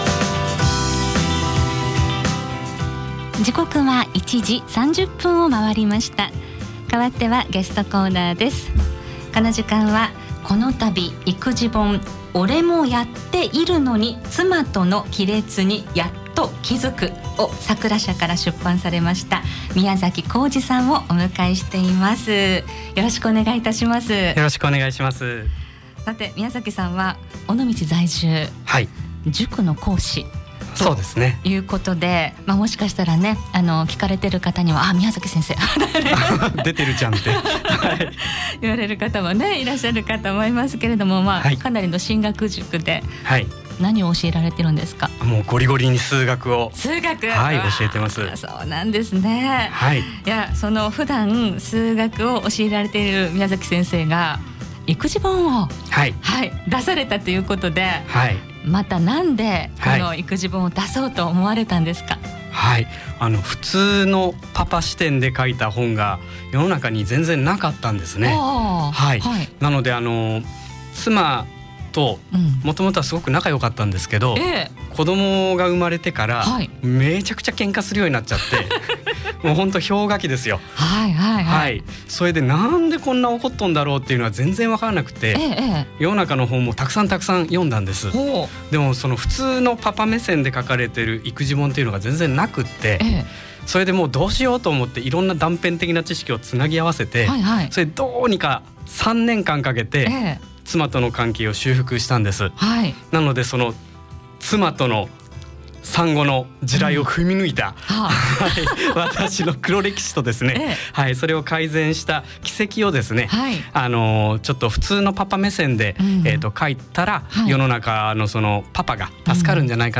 出版の経緯などについてお聞きしました。